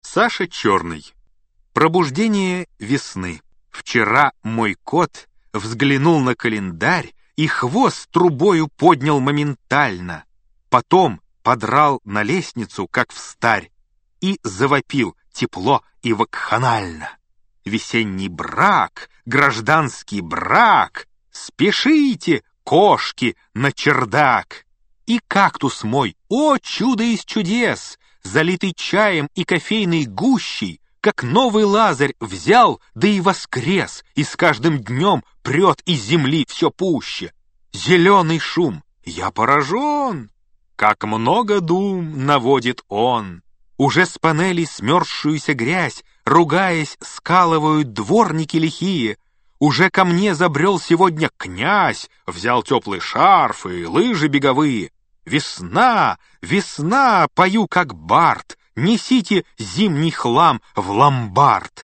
Аудиокнига Избранное | Библиотека аудиокниг
Прослушать и бесплатно скачать фрагмент аудиокниги